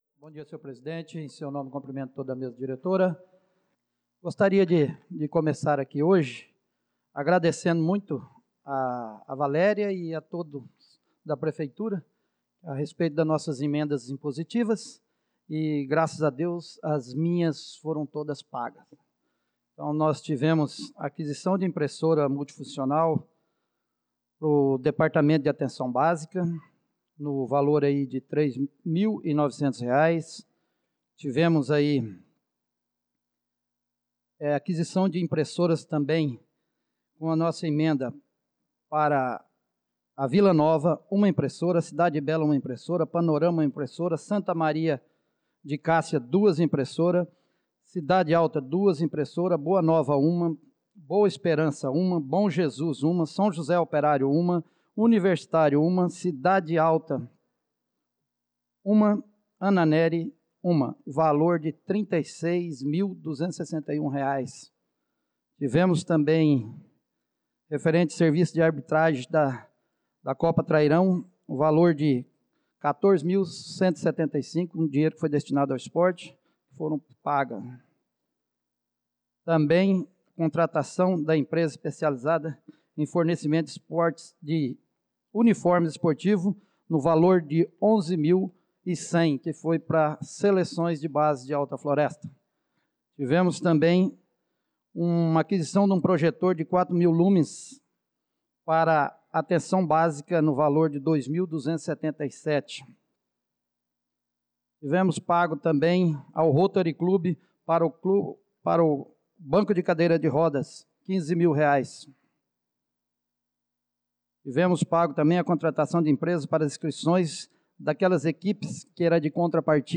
Pronunciamento do vereador Marcos Menin na Sessão Ordinária do dia 25/03/2025